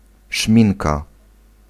Ääntäminen
Ääntäminen US : IPA : [ˈlɪp.ˌstɪk] Haettu sana löytyi näillä lähdekielillä: englanti Käännös Ääninäyte Substantiivit 1. szminka {f} Määritelmät Substantiivi (uncountable) Makeup for the lips .